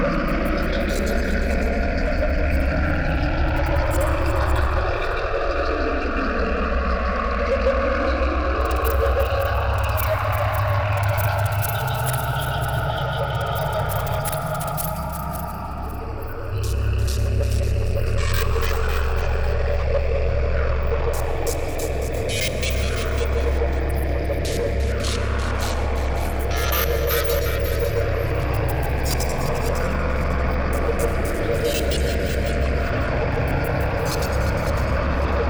Ambience_Hell_00.wav